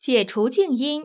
conf-unmuted.wav